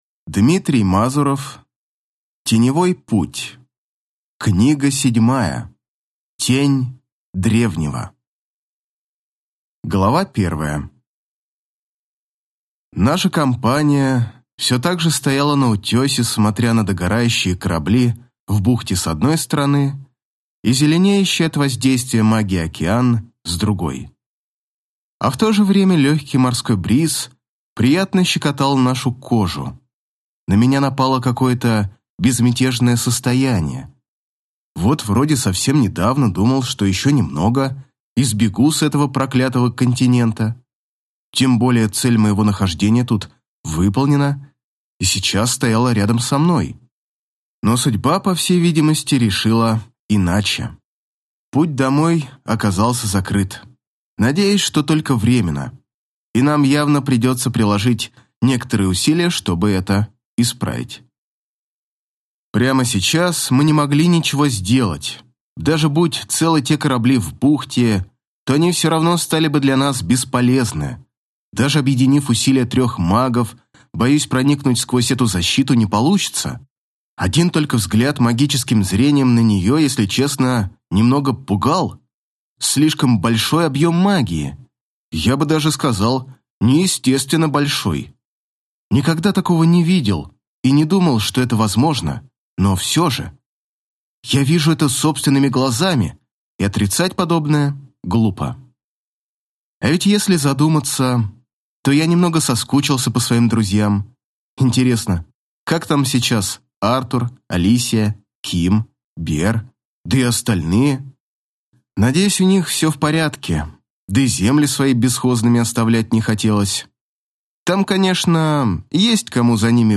Аудиокнига Тень Древнего | Библиотека аудиокниг